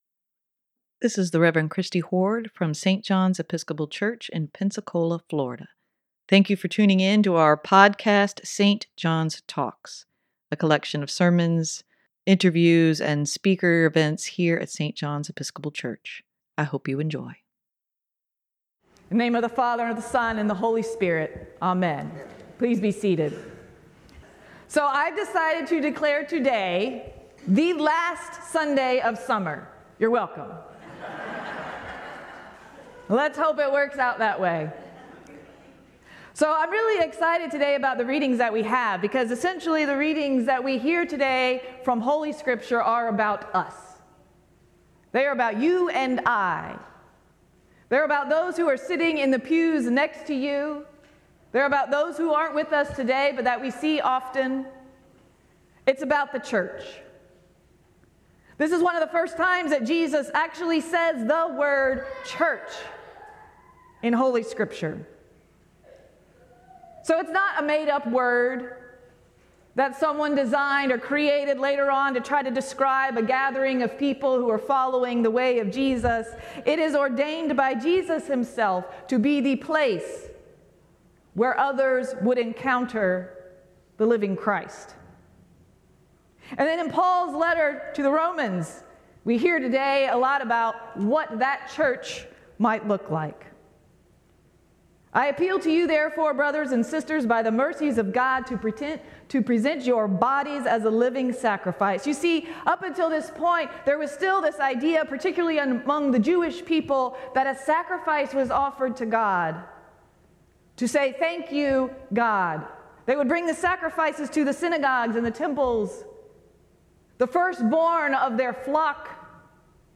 Sermon for Aug. 27, 2023: The body of Christ must have a unified goal - St. John's Episcopal Church
sermon-8-27-23.mp3